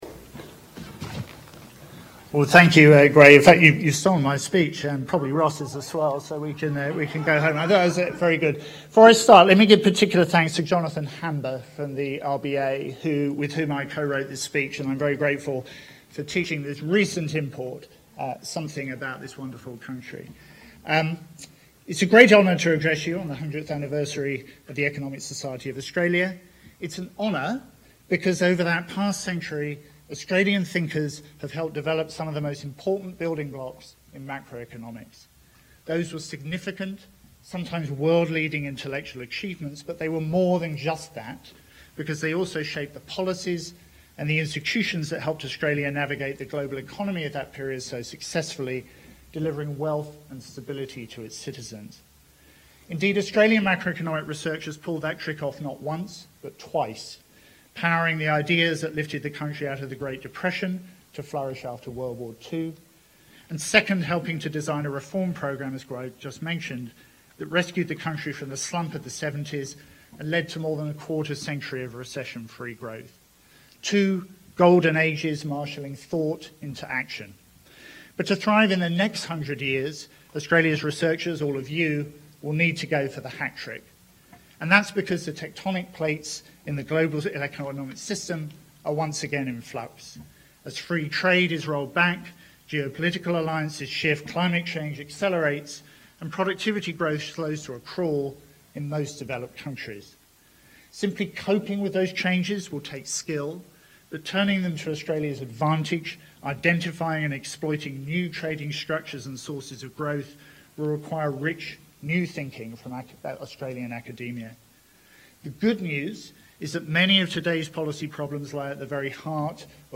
In this speech to the Economics Society of Australia (Australian Conference of Economists), Andrew Hauser, Deputy Governor, reflects on how Australian thinkers have helped to develop some of the most important building blocks in open economy macroeconomics over the past century, and where they can contribute over the next century.